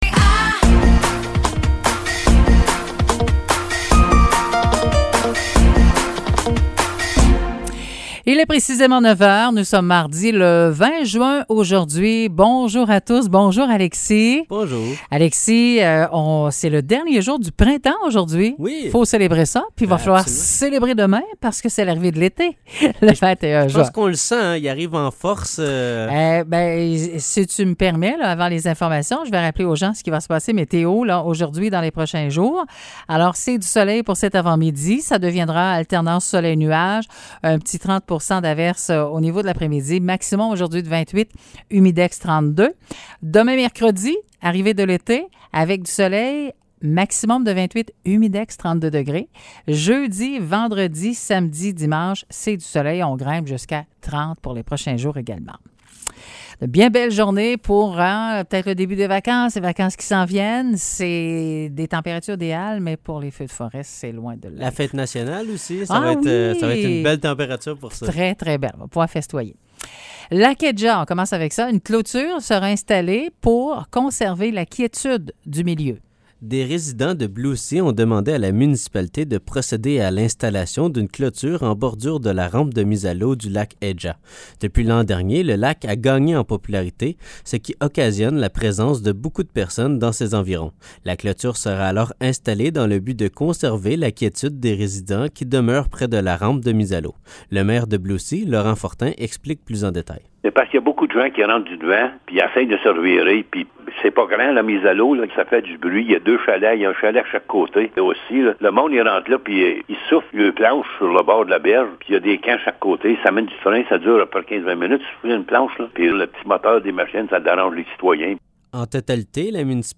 Nouvelles locales - 20 juin 2023 - 9 h